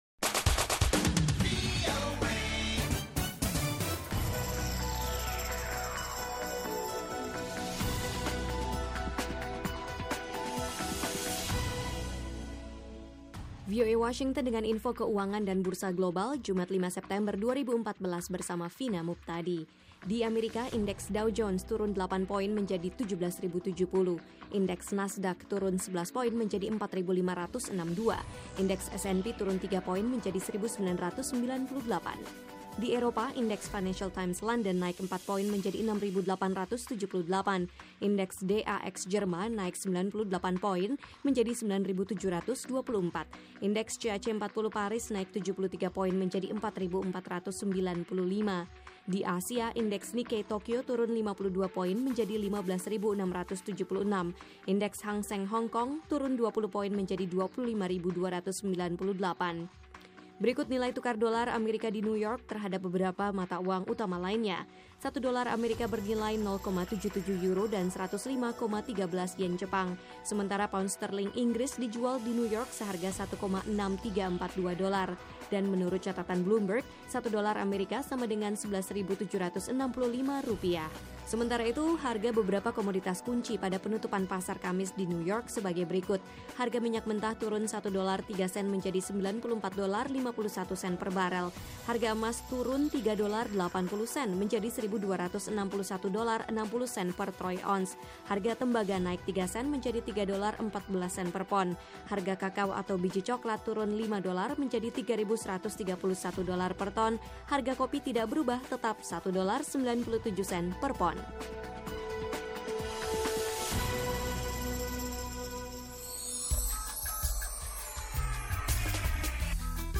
Laporan saham dan berita ekonomi